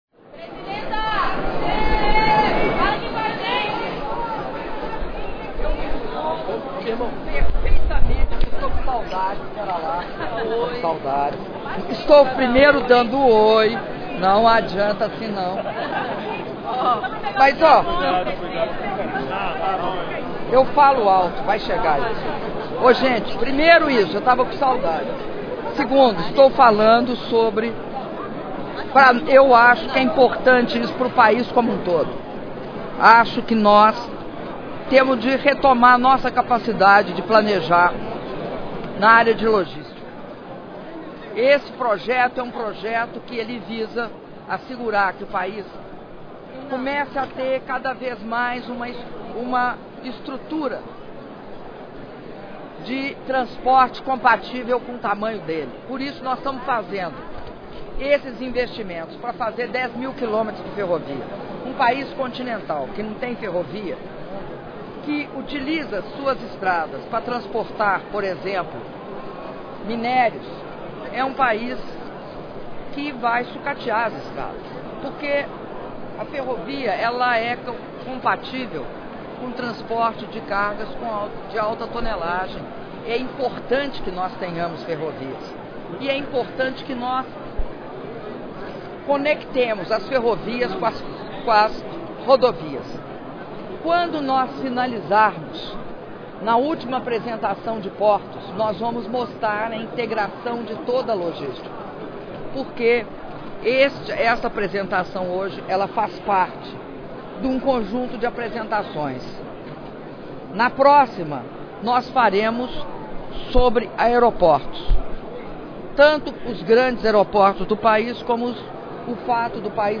audio da entrevista concedida pela presidenta da republica dilma rousseff apos cerimonia de anuncio do programa de concessoes de rodovias e ferrovias brasilia df 5min13s